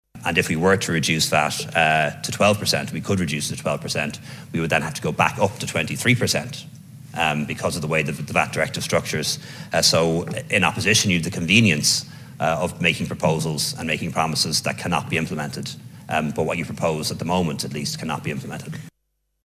Sinn Féin said VAT on fuel needs to be scrapped immediately – something Leo Varadkar says isn’t possible: